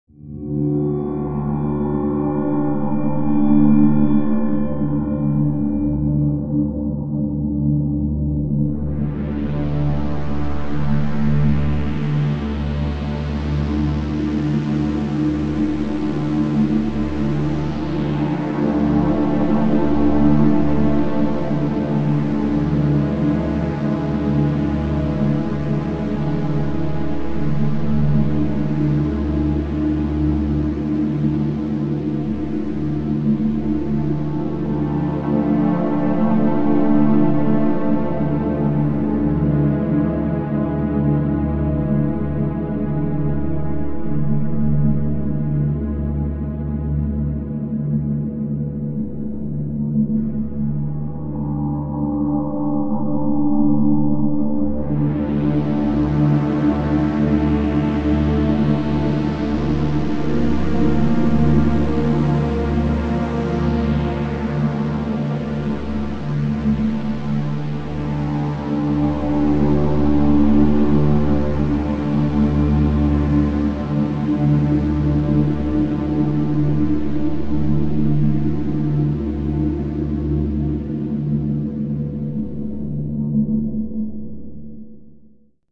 Ioncross-Total-War / DATA / AUDIO / SOUNDS / AMBIENCES / zone_field_asteroid_rock.wav
zone_field_asteroid_rock.wav